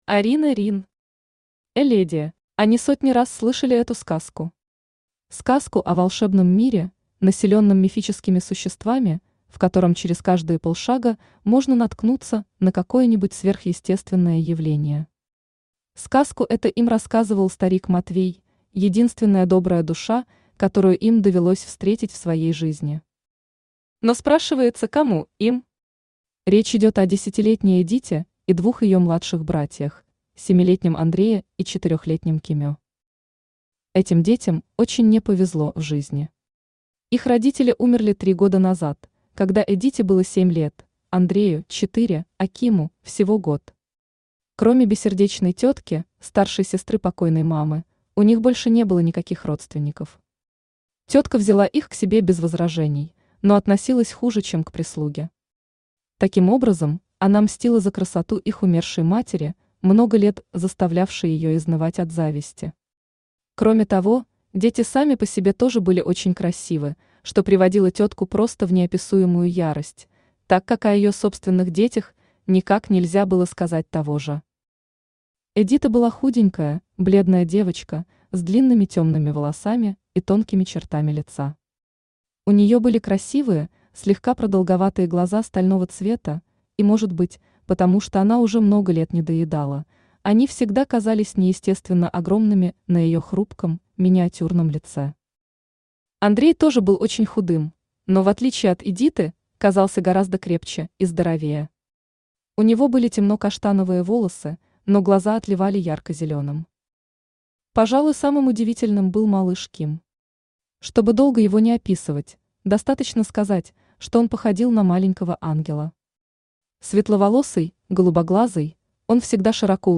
Аудиокнига Эледия | Библиотека аудиокниг
Aудиокнига Эледия Автор Арина Рин Читает аудиокнигу Авточтец ЛитРес.